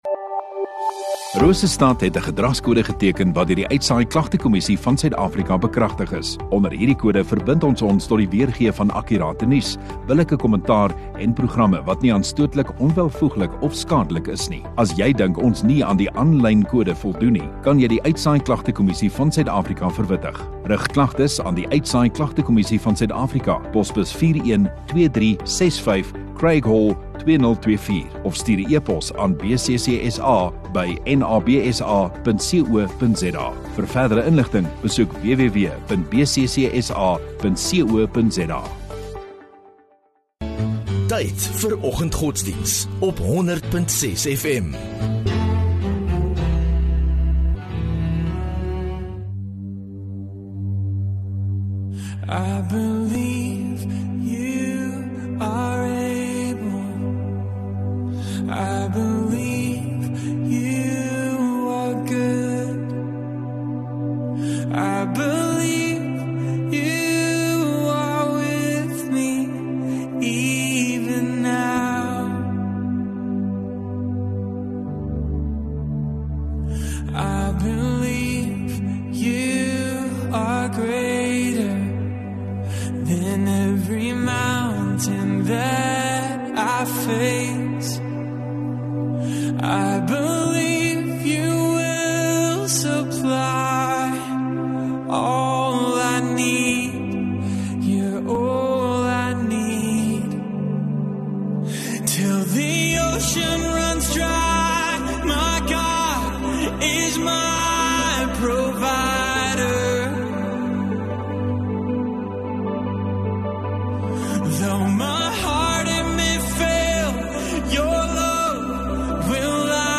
13 Feb Donderdag Oggenddiens